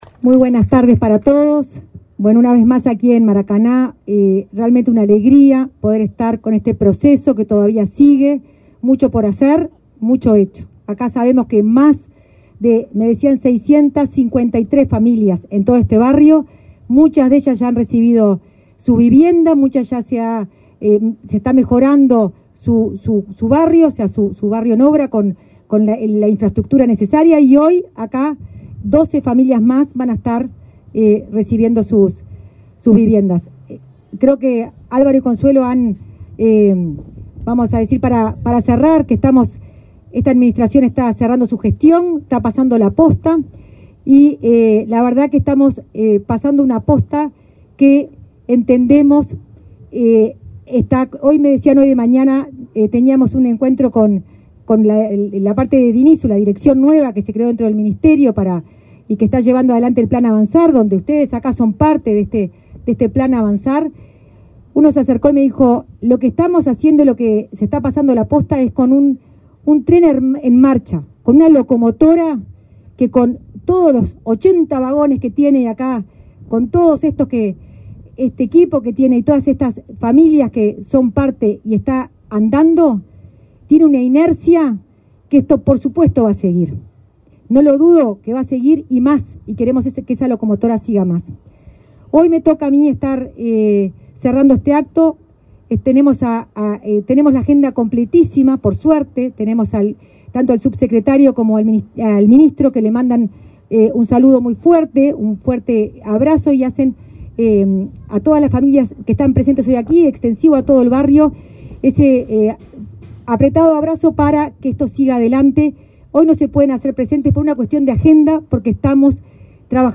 Declaraciones a la prensa de la directora nacional de Integración Social y Urbana, Florencia Arbeleche
Declaraciones a la prensa de la directora nacional de Integración Social y Urbana, Florencia Arbeleche 27/02/2025 Compartir Facebook X Copiar enlace WhatsApp LinkedIn Tras participar en la entrega de 12 soluciones habitacionales, en el marco del plan Avanzar, este 27 de febrero, en el barrio Maracaná Sur de Montevideo, la directora nacional de Integración Social y Urbana, Florencia Arbeleche, realizó declaraciones a la prensa.